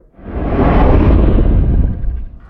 Звуки Годзиллы
Здесь вы найдете его мощный рев, грохот шагов, звуки разрушений и другие эффекты из фильмов и комиксов.
Годзилла рычит громко и быстро